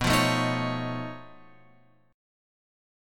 B 9th Flat 5th